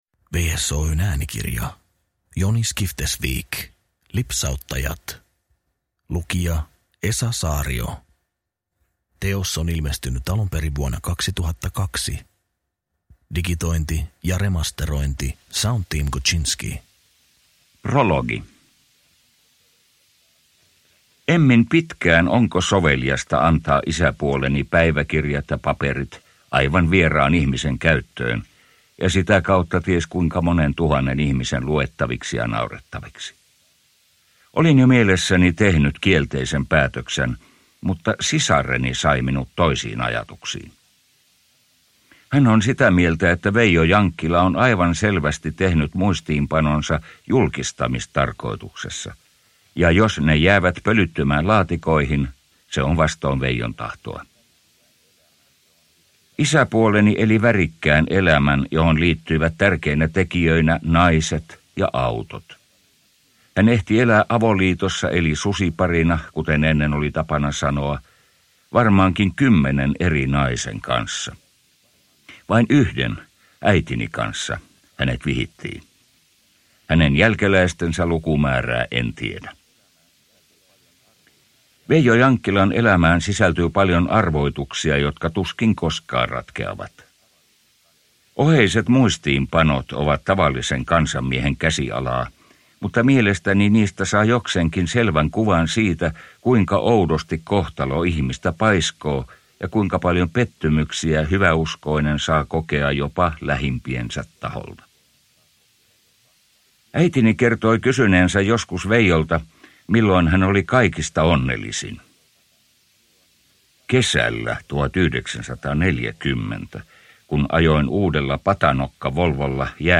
Lipsauttajat – Ljudbok – Laddas ner